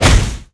Explo_Small.wav